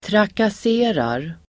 Uttal: [trakas'e:rar]